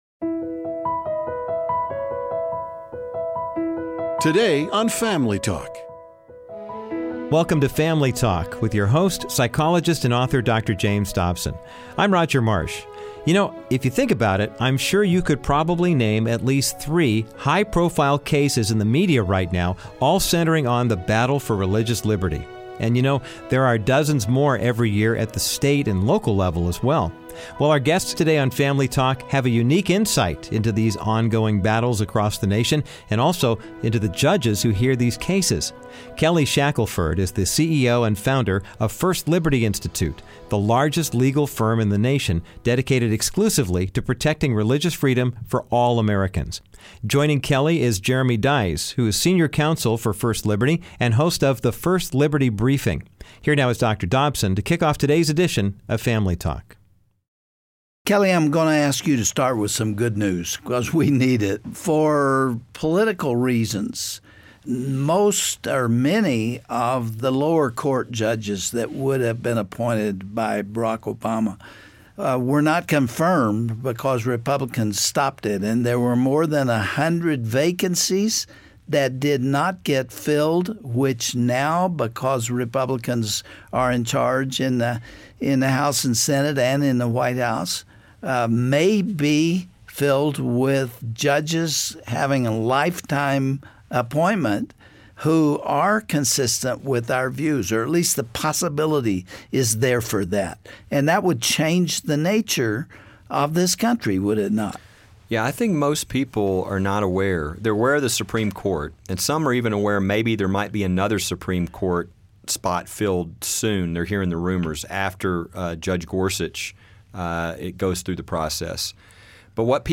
Todays guests have a unique insight into these on-going battles across the nation and the judges who hear the cases. Dont miss their enlightening conversation today on Family Talk with Dr. James Dobson.